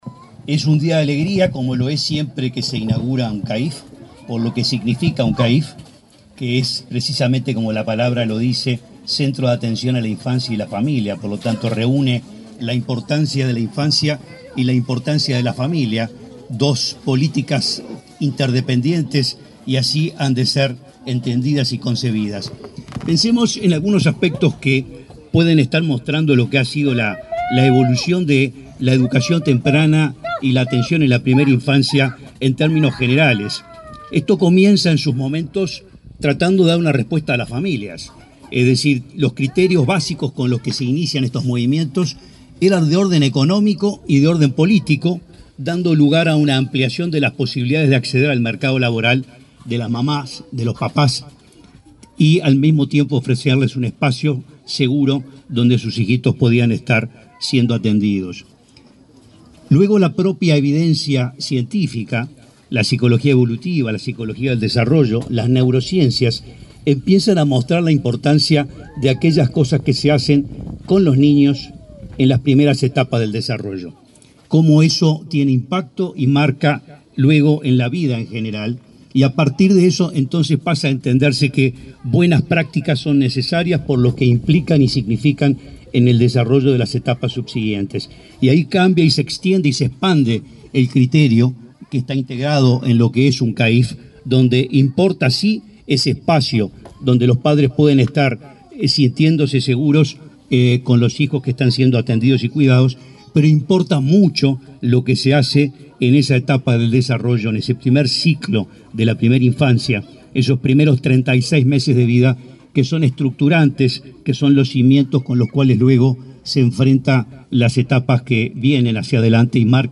Palabras del presidente del INAU, Guillermo Fosatti
Palabras del presidente del INAU, Guillermo Fosatti 03/09/2024 Compartir Facebook X Copiar enlace WhatsApp LinkedIn El presidente del Instituto del Niño y el Adolescente del Uruguay (INAU), Guillermo Fosatti, participó en la inauguración de un centro de atención a la infancia y la familia (CAIF) en la localidad de Las Piedras, departamento de Canelones.